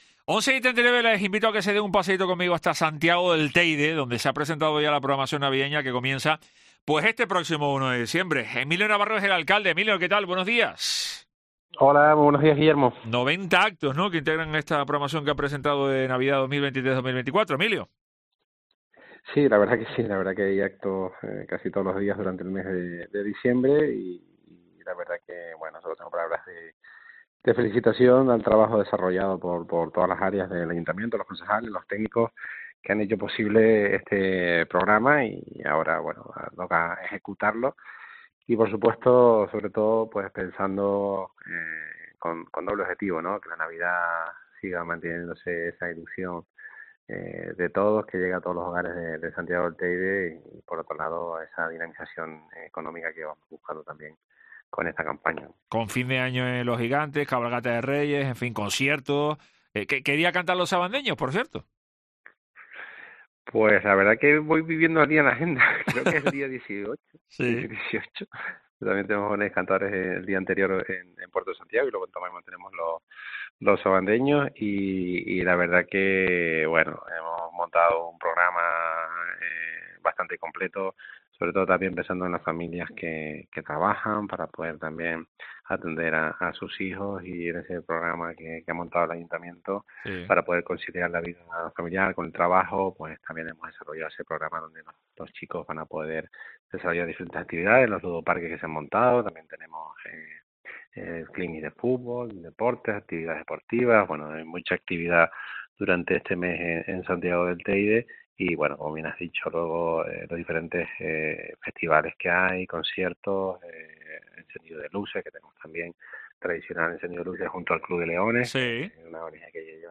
Emilio Navarro, alcalde de de Santiago del Teide, presenta el programa de Navidad del municipio